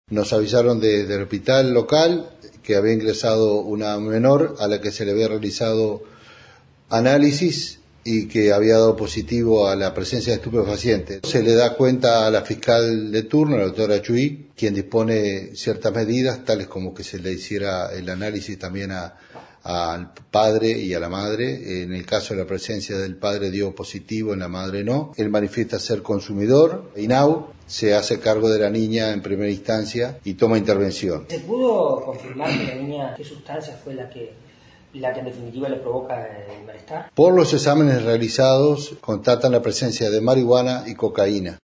Informe: corresponsal en Río Negro